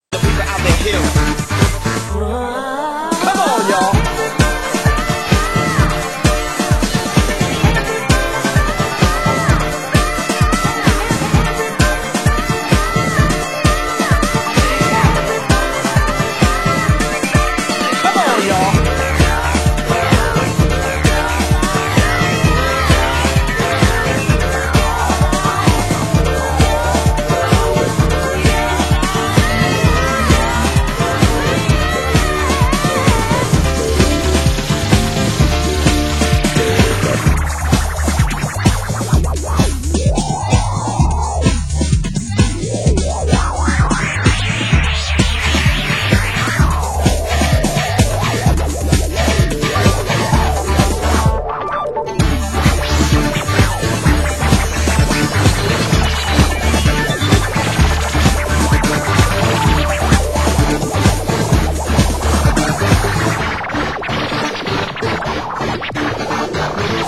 Genre: Euro Rave (1990-92)